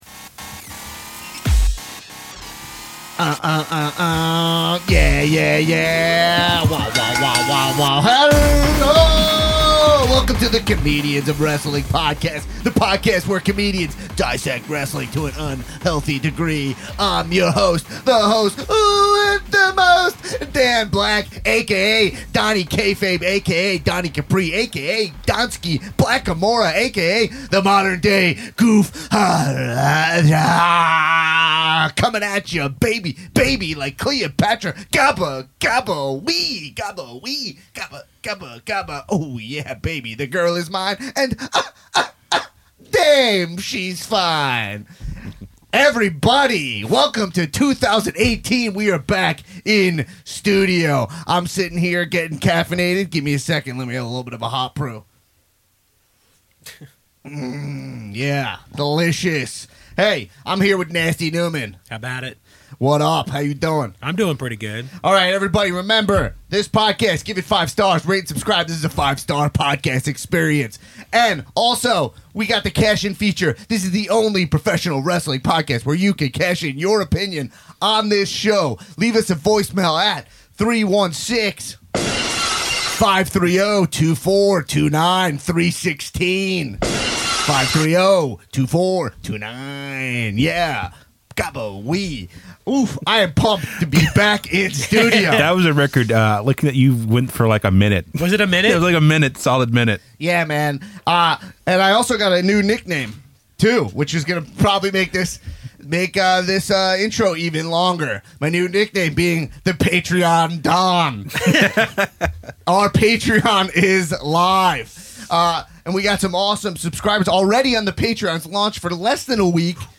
WE’RE BACK IN THE UCB STUDIOS, COW-HEADS!